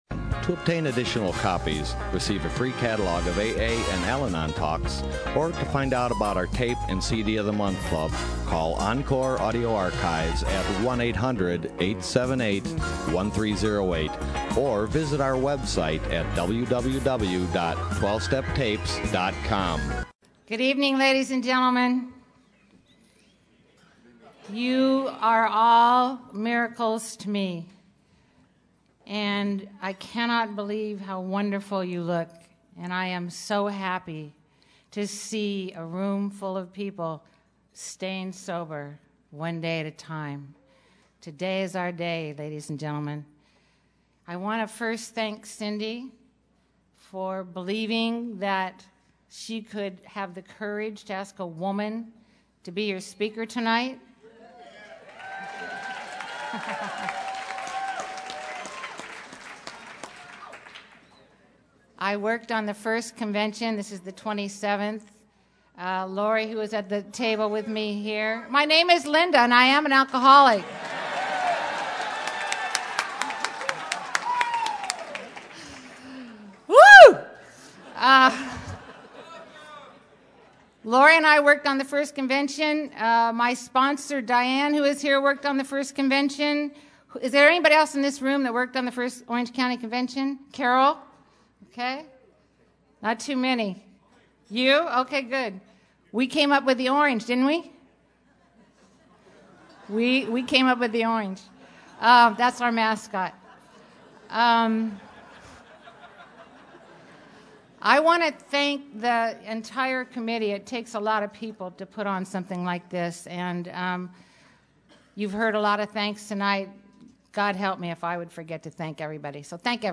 Orange County AA Convention 2012